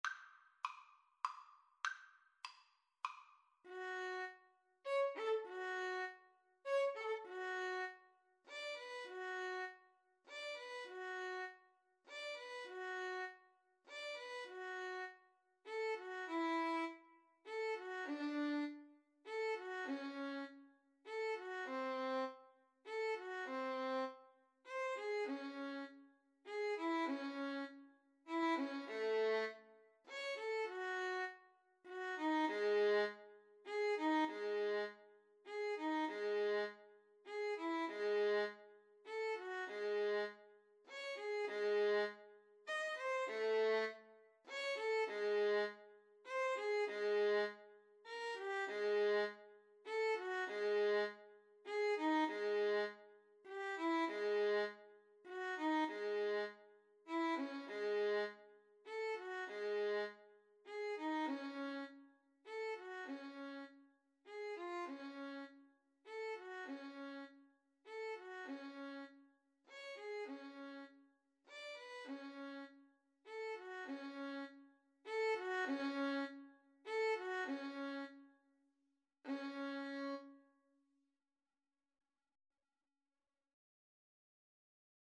Con moto, leggieramente
Classical (View more Classical Violin Duet Music)